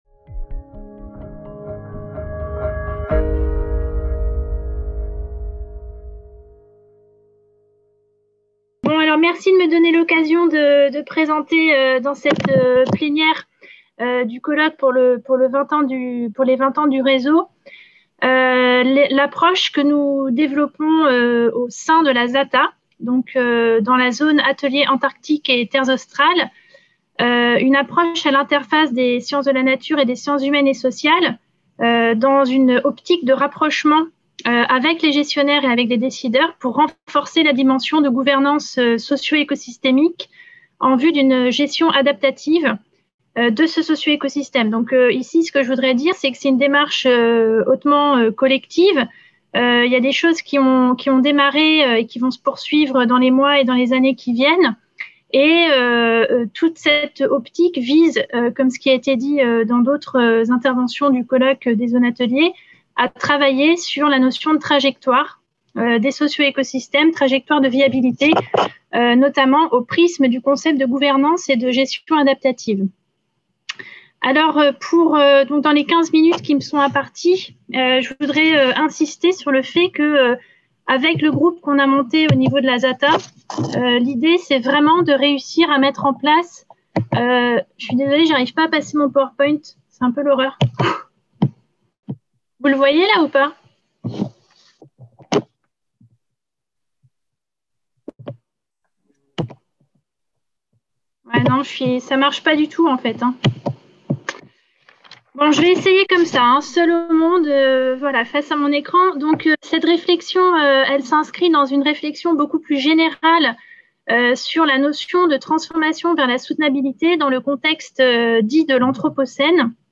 Conférence plénière - La transformation vers la soutenabilité, de la théorie à la pratique : la ZATA, un modèle d'étude et d'action pertinent et efficace.